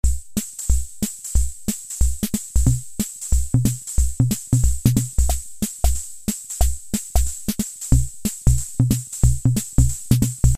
Auto-Vari 64 is a rhythm box, designed in 1973.
There are sixteen different two-measure rhythm patterns, each one with four different variations (hence "64").
There seems to be about 10 different sounds, all of excellent analogue quality.
This governs the balance between the brighter sounds (cymbals, etc.) and the darker drum sounds.